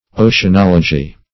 Oceanology \O`cean*ol"o*gy\, n. [Ocean + -logy.]